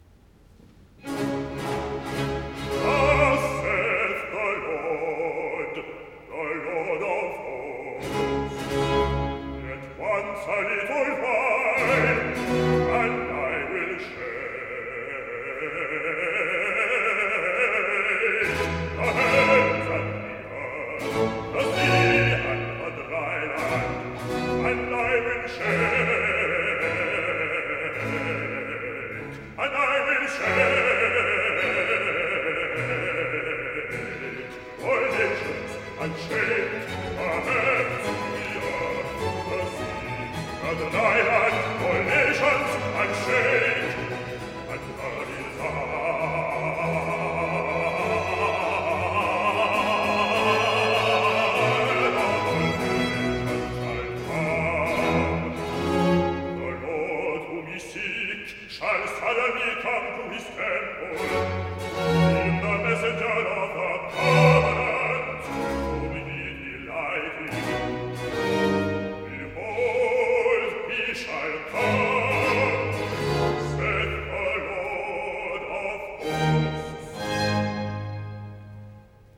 Recitative-bass